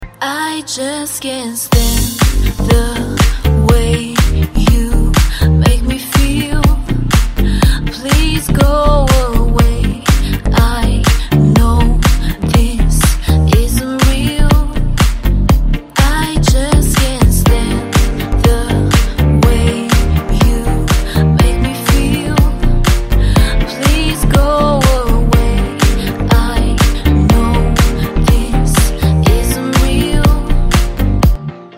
• Качество: 256, Stereo
dance
house